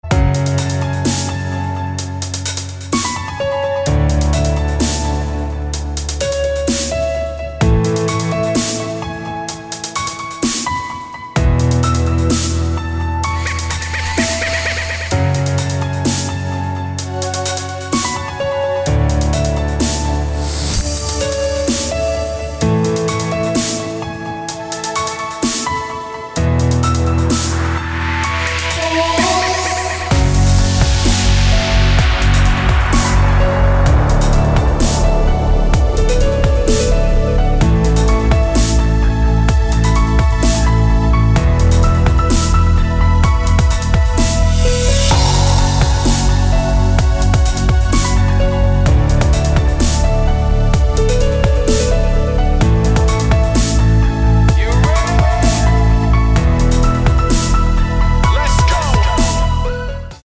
красивые
dance
электронная музыка
спокойные
пианино